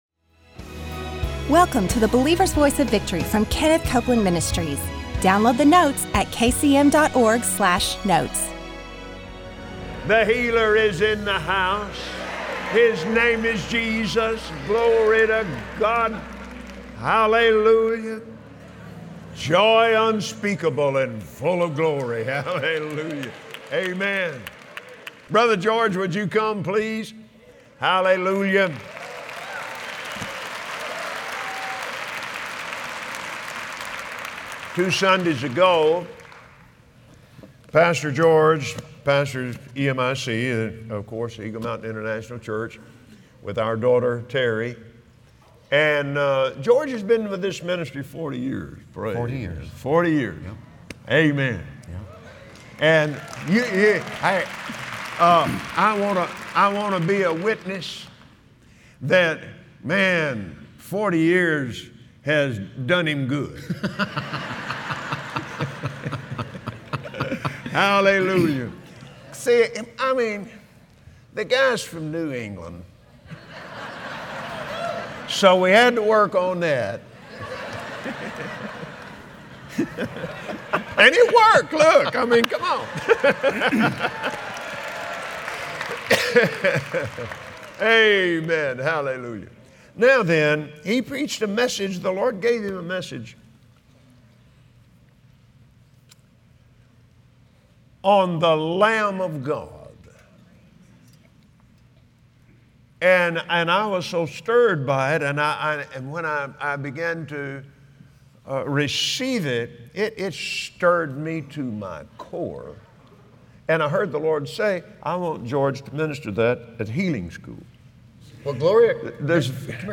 Believers Voice of Victory Audio Broadcast for Monday 08/01/2016 What does the Passover Lamb signify? And how is it connected to receiving our healing today? Join Kenneth Copeland as he opens up the Believer’s Voice of Victory, and explains how mixing faith with God’s Word brings the desired end result.